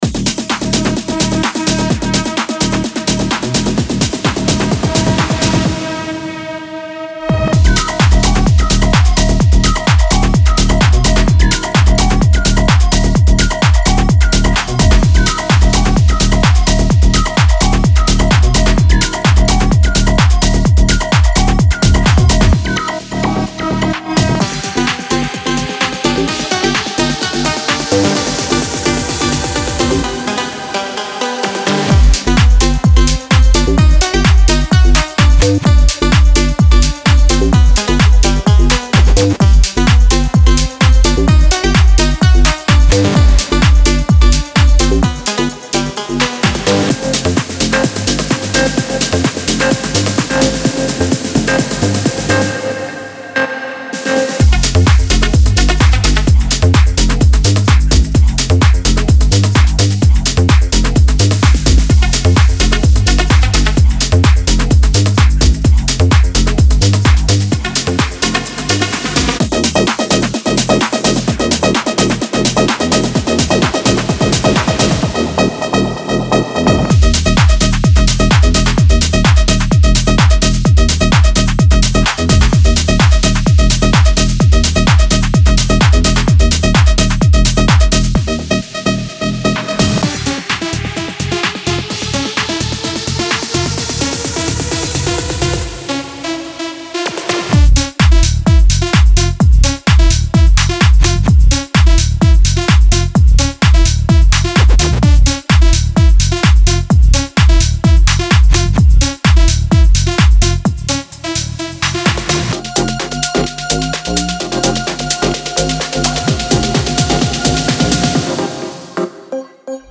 House Tech House
hype-filled sounds and samples for Tech House Beats.